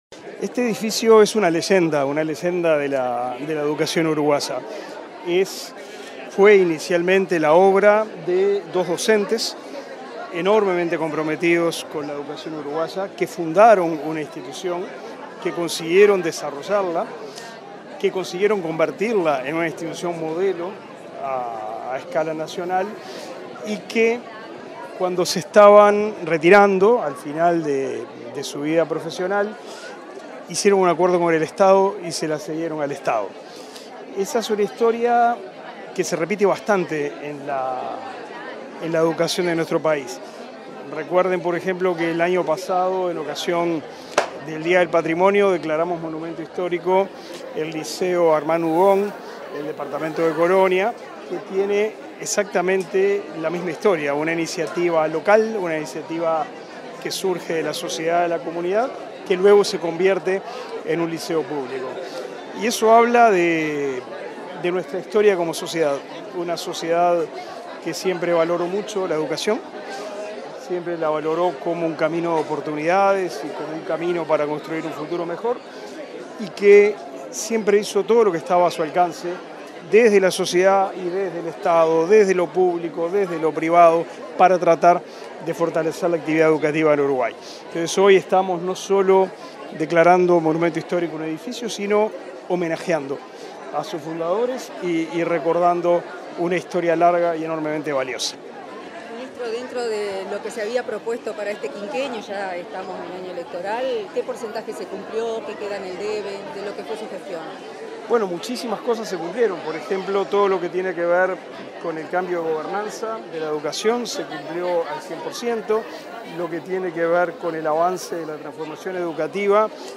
Declaraciones del ministro de Educación y Cultura, Pablo da Silveira
Declaraciones del ministro de Educación y Cultura, Pablo da Silveira 11/04/2024 Compartir Facebook X Copiar enlace WhatsApp LinkedIn Tras la declaratoria del liceo n.°1 de Salto como Monumento Histórico Nacional, este 11 de abril, el ministro de Educación y Cultura, Pablo da Silveira, realizó declaraciones a la prensa.